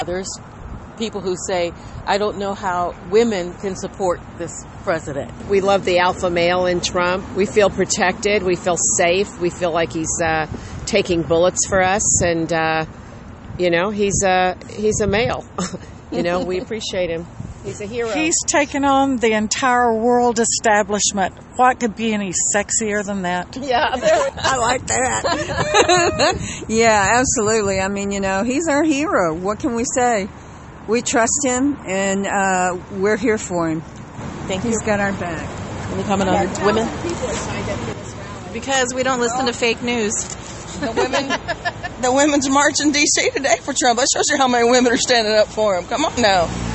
Dallas (WBAP/KLIF) – Outside the American Airlines Center, a group of female Trump supporters answered to those who ask, “How can women support this president.”